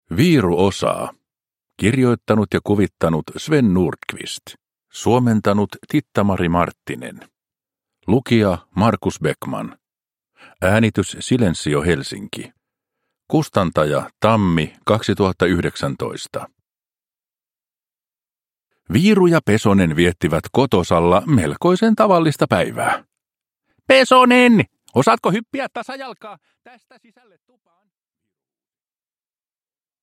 Viiru osaa – Ljudbok – Laddas ner